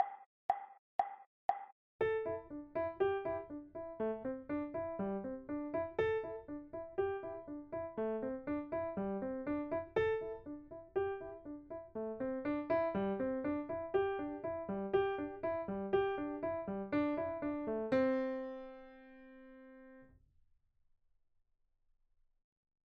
1. You will be provided with a melody in a piano. Before hearing it, the tempo will be clarified by a metronome playing the first four beats.
In order to focus on the goals of the exercise, all the melodies are written in 4/4 and the key of C major.
We will have “Do”, “Re”, “Mi”, “Sol” and “La” in two octaves.
At 120 BPM playing constantly in eight notes, there will be a good number of pitches to transcribe.
There’s also an added difficulty for this exercise: starting in a different pitch than the tonic.